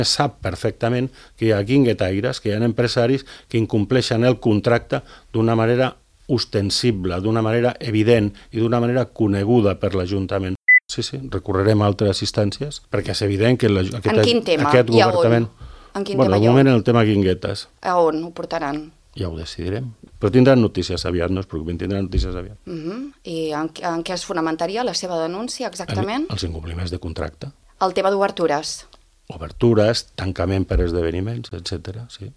Ho ha exposat el portaveu dels comuns, Sebastián Tejada, a l’ENTREVISTA POLÍTICA de Ràdio Calella TV.